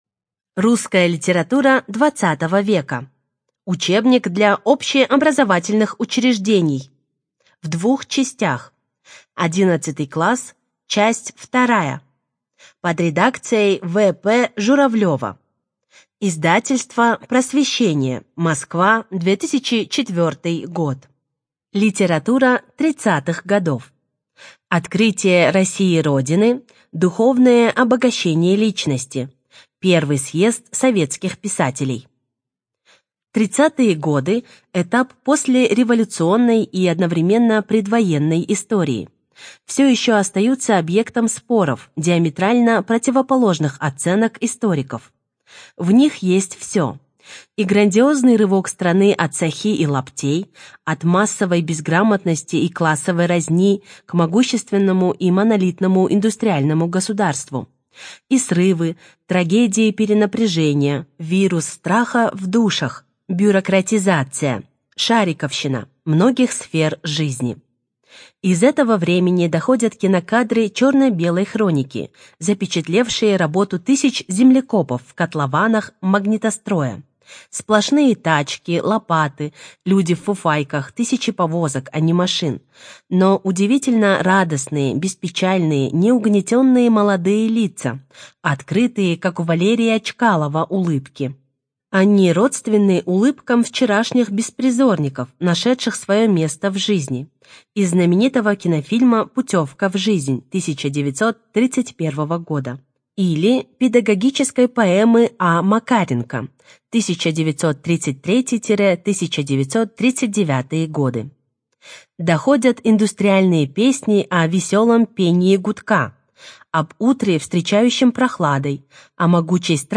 АвторАудиопособия
Студия звукозаписиСоциальный центр "Равные возможности" (Бишкек)